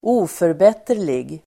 Uttal: [²'o:förbet:er_lig]